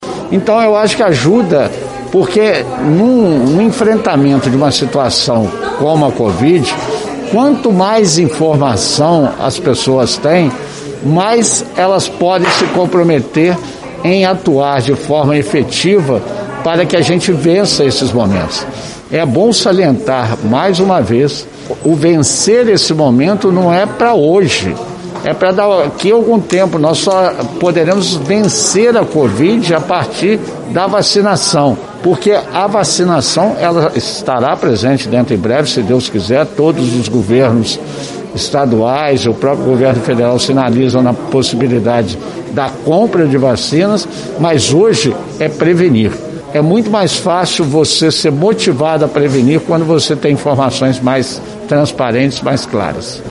A Prefeitura de Juiz de Fora (PJF) apresentou, em coletiva de imprensa nesta tarde de terça-feira, 1º, o novo modelo do Painel Gerencial, que detalha a situação do coronavírus na cidade.
prefeito-novo-painel-gerencial.mp3